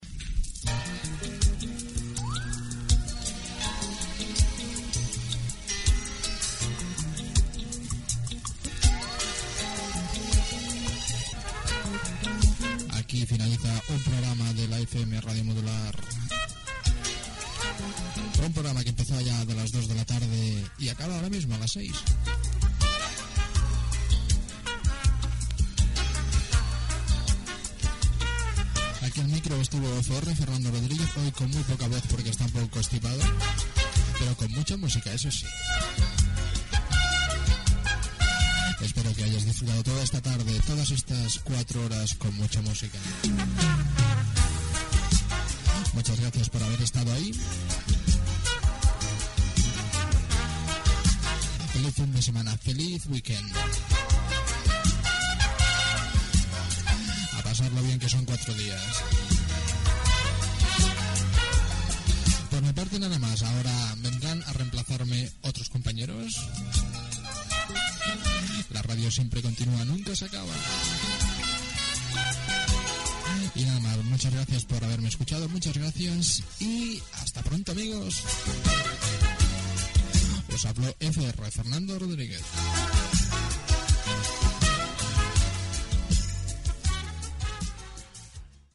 Comiat del programa amb identificació de l'emissora
Musical